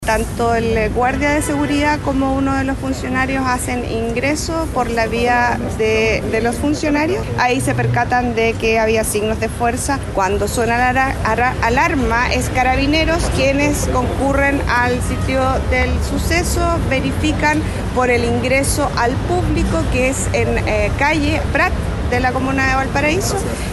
Así lo señaló la fiscal de turno de instrucción y flagrancia de la región de Valparaíso, Daniela Quevedo, precisando que “tanto el guardia de seguridad como uno de los funcionarios hacen ingreso por la vía de los funcionarios, ahí se percatan de que había signos de fuerza”.
cu-banco-itau-robo-fiscal.mp3